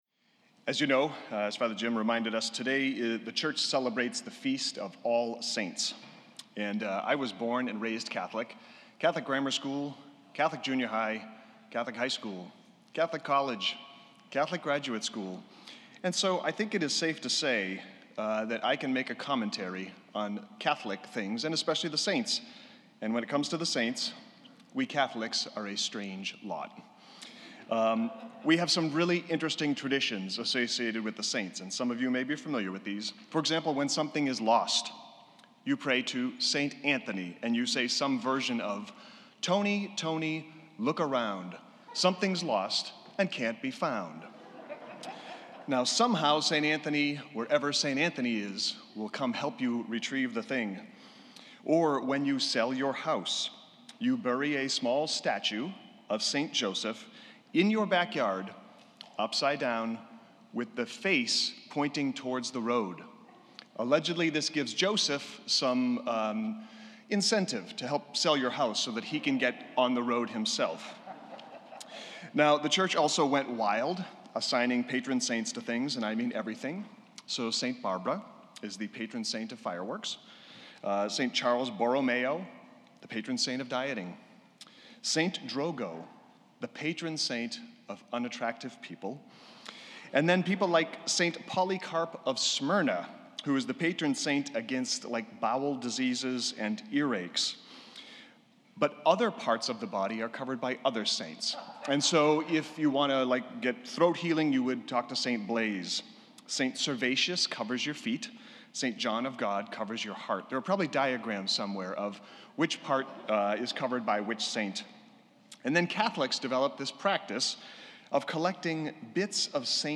November 1 All Saints homily As you may know, today the church celebrates the feast of All Saints.